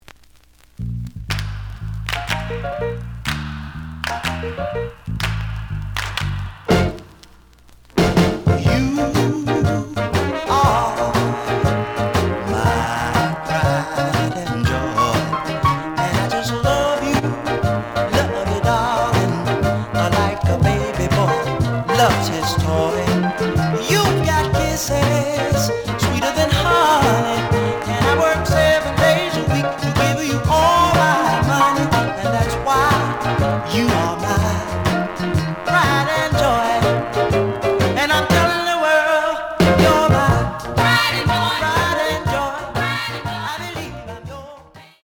The audio sample is recorded from the actual item.
●Genre: Rhythm And Blues / Rock 'n' Roll